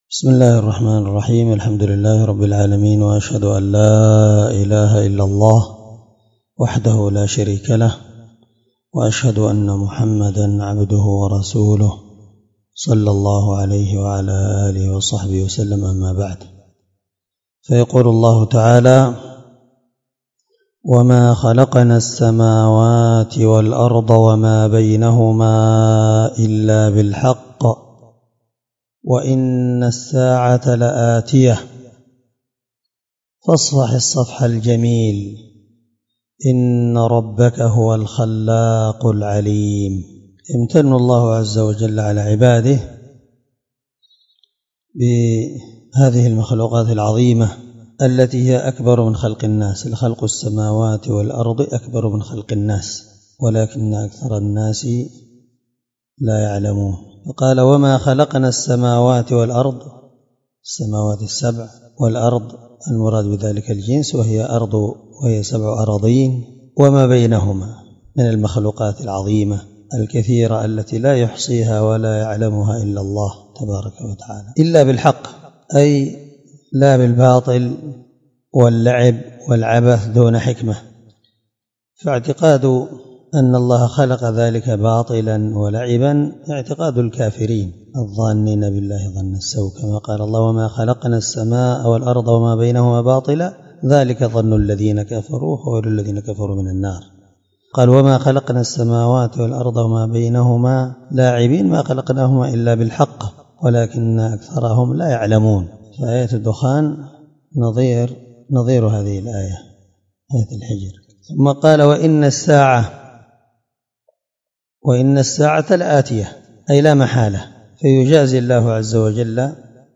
721الدرس12 تفسير آية (85-86) من سورة الحجر من تفسير القرآن الكريم مع قراءة لتفسير السعدي